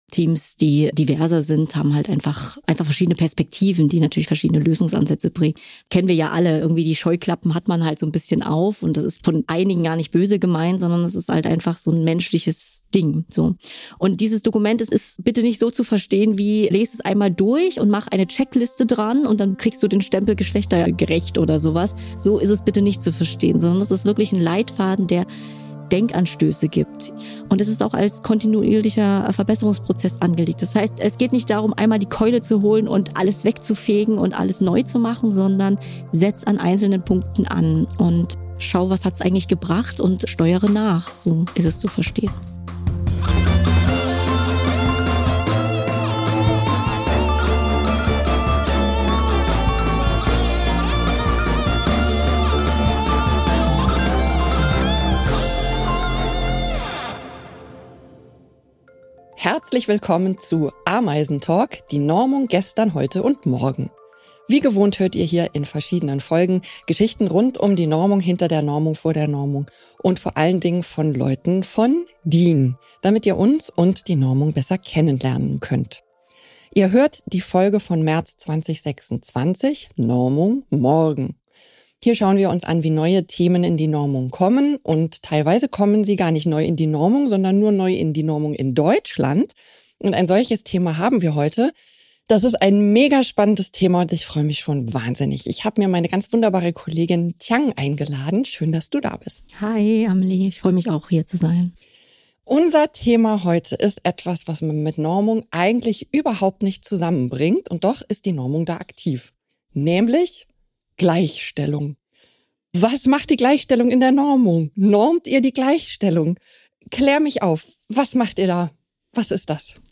Ein Gespräch über Verantwortung, Mitgestaltung und die Frage, wie Normen gesellschaftliche Entwicklungen konstruktiv begleiten können.